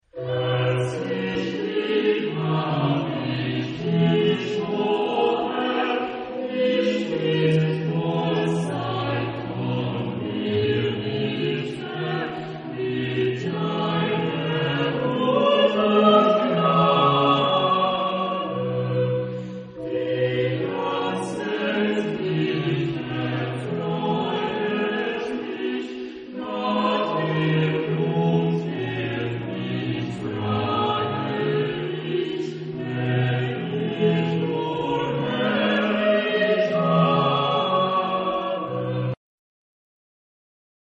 Genre-Style-Forme : Baroque ; Sacré ; Choral
Type de choeur : SATB  (4 voix mixtes )
Tonalité : mi bémol majeur